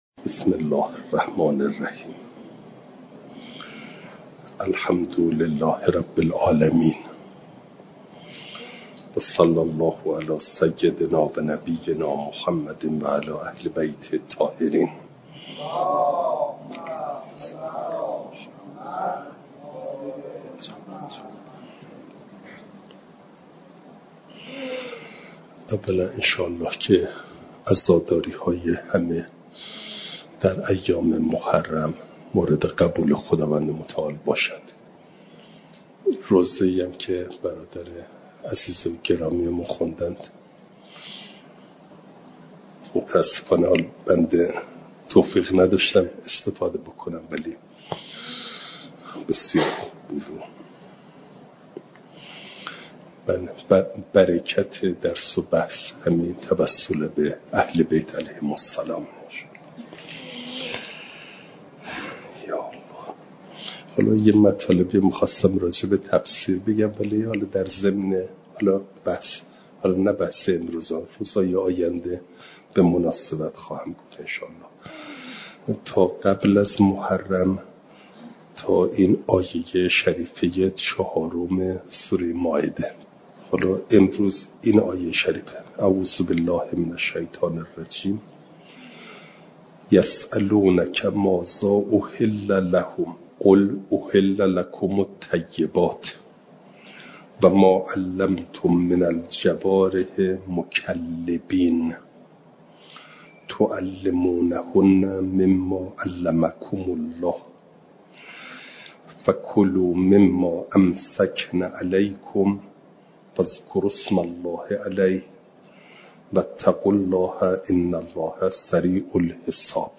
جلسه چهارصد و شانزده درس تفسیر مجمع البیان